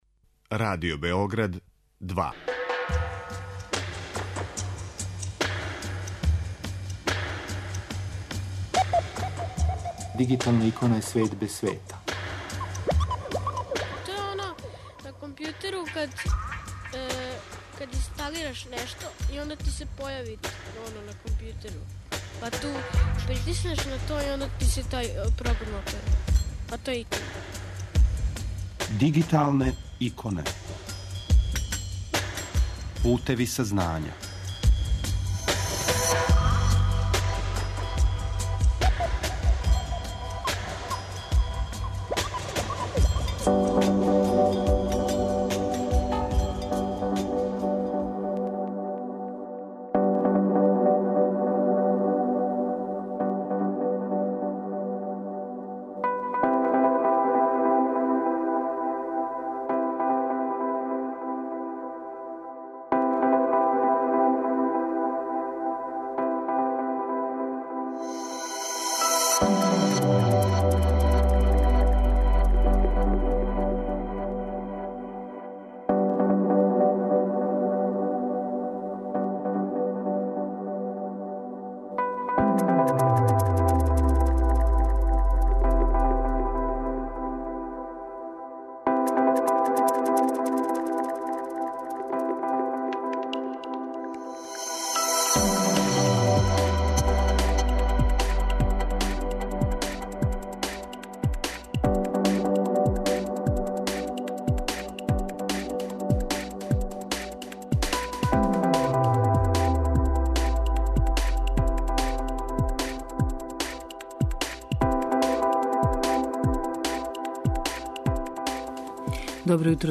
Белешке из Италије слушаћете током летњих месеци, а прве разговоре емитујемо у данашњој емисији.